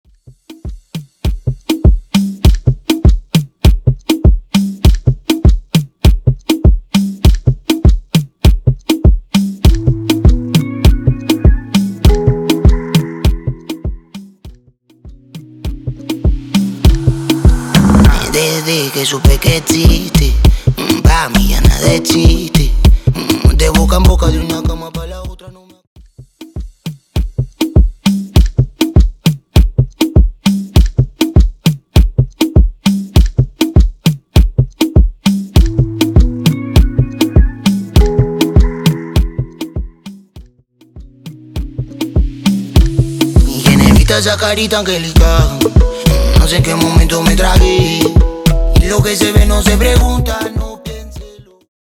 Intro Dirty, Coro Dirty